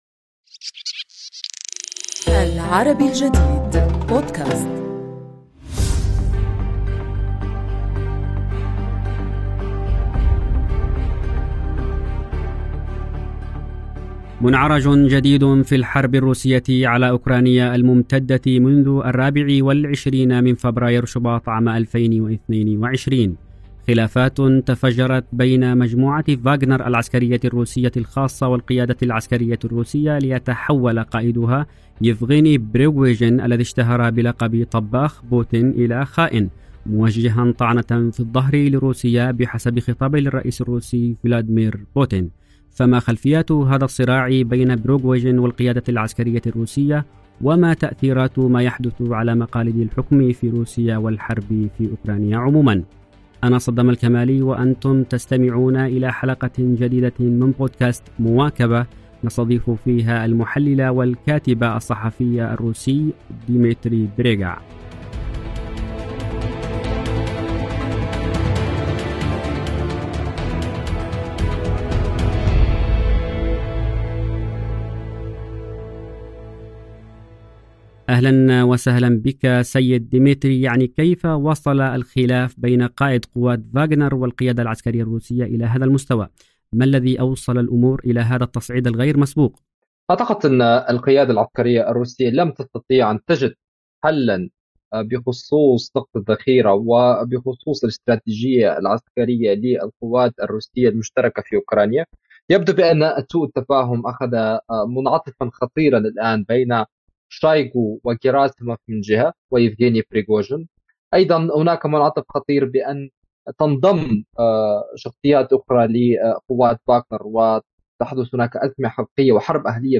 المحلل والكاتب الصحافي الروسي